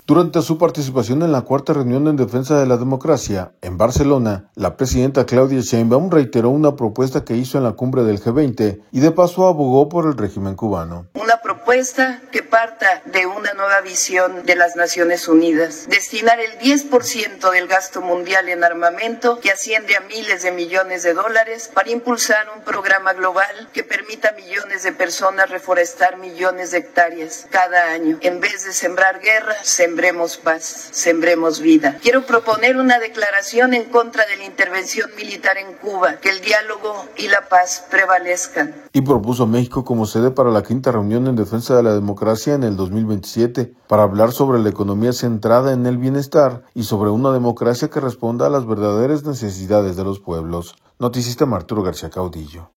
audio Durante su participación en la Cuarta Reunión en Defensa de la Democracia, en Barcelona, la presidenta Claudia Sheinbaum, reiteró una propuesta que hizo en la Cumbre del G20 y de paso abogó por el régimen cubano.